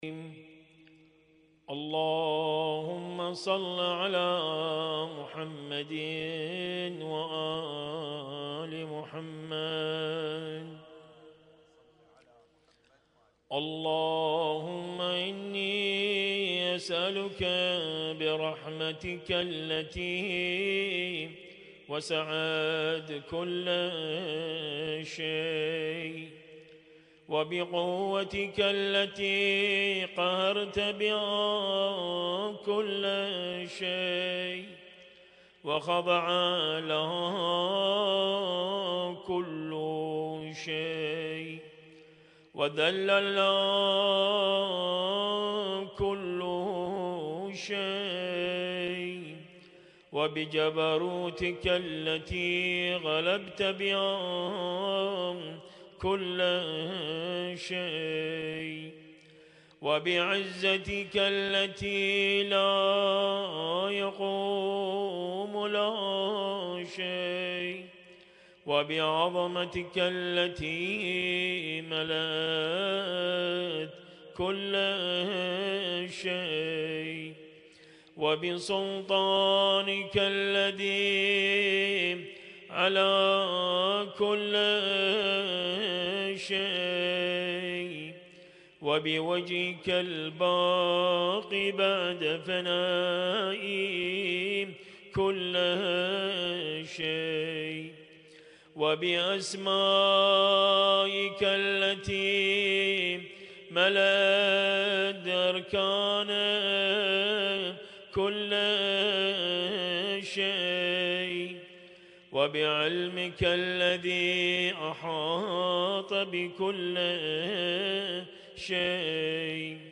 Husainyt Alnoor Rumaithiya Kuwait
القارئ: - الرادود
اسم التصنيف: المـكتبة الصــوتيه >> الادعية >> دعاء كميل